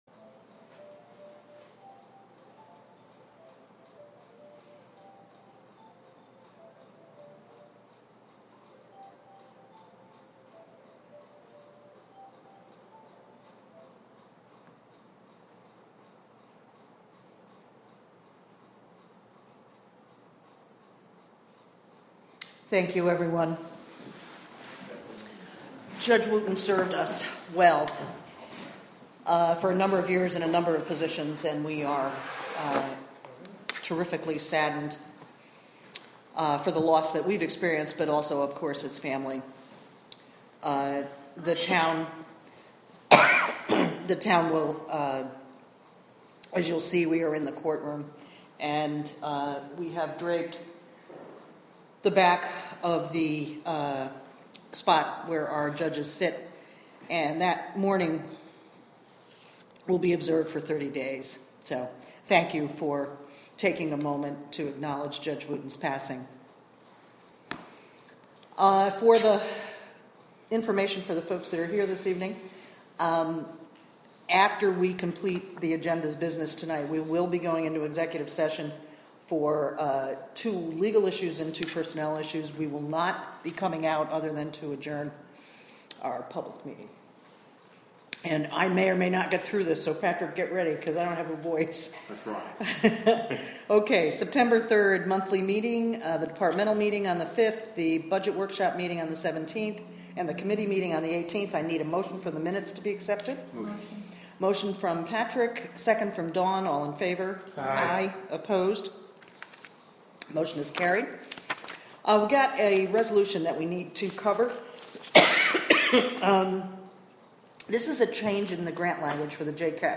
Recorded from a live webstream created through the Wave Farm Radio app with the Town of Catskill. Town of Catskill meeting audio from Oct. 1, 2019.